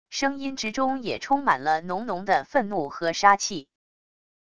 声音之中也充满了浓浓的愤怒和杀气wav音频生成系统WAV Audio Player